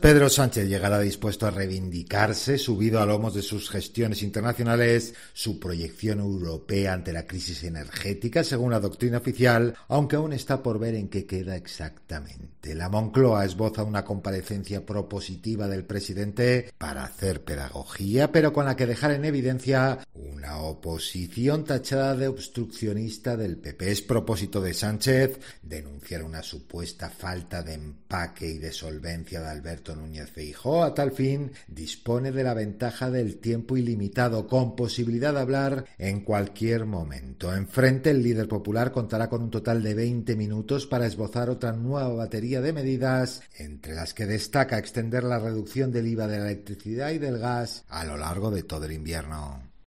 Sigue en directo el cara a cara entre Sánchez y Feijóo en el Senado para discutir sobre las medidas anticrisis